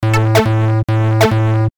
描述：用果味循环制作的简单真实的贝司线
Tag: 100 bpm Chill Out Loops Bass Loops 413.58 KB wav Key : E